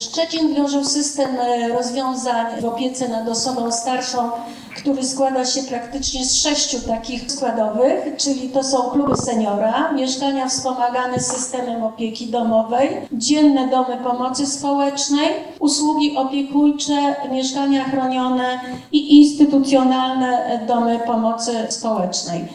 „Mieszkania wspomagane w polityce mieszkaniowej i społecznej samorządów” – konferencja pod takim hasłem odbywa się w Stargardzkim Centrum Kultury.